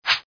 thwip.mp3